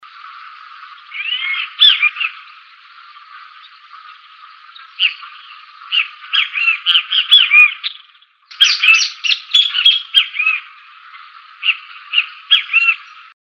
Great Kiskadee (Pitangus sulphuratus)
Sex: Indistinguishable
Location or protected area: Reserva Ecológica Costanera Sur (RECS)
Condition: Wild
Certainty: Photographed, Recorded vocal